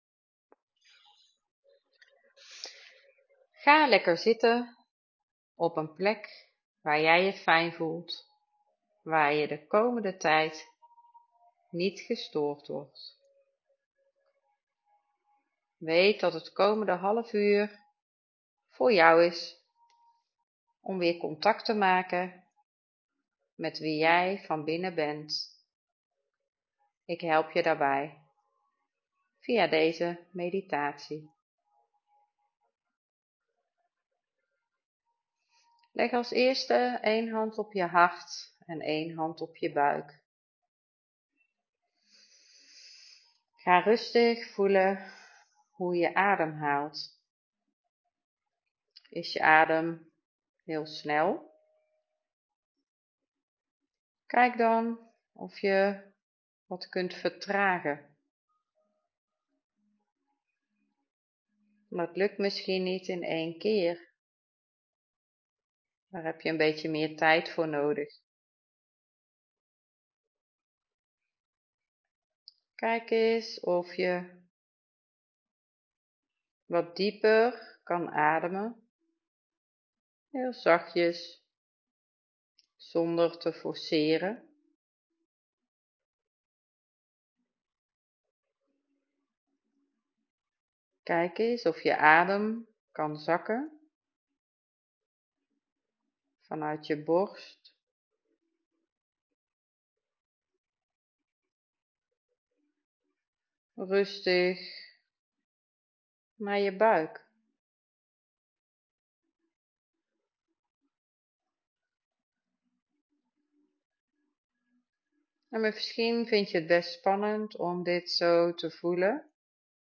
Om meer rust in je systeem te kunnen ervaren, kun je onderstaande meditatie beluisteren.